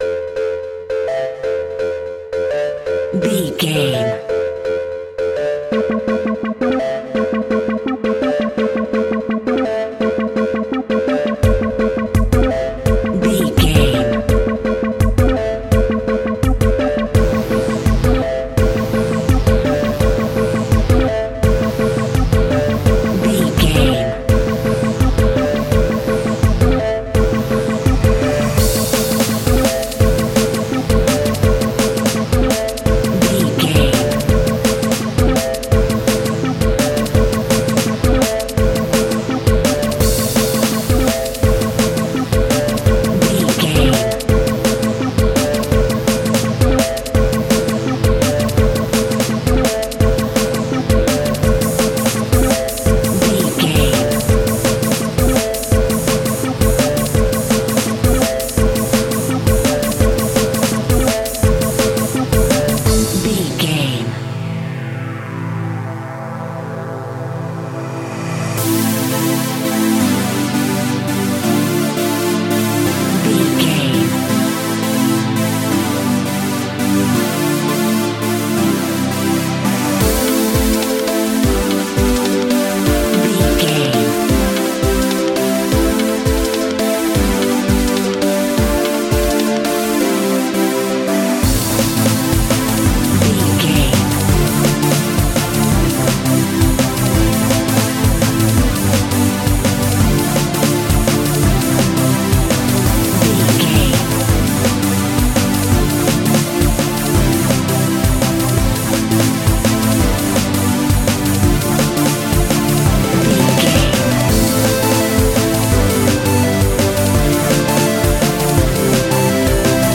Aeolian/Minor
Fast
aggressive
dark
groovy
industrial
frantic
synthesiser
drum machine
electronic
sub bass
synth leads
synth bass